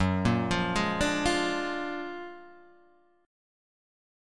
F#+M9 Chord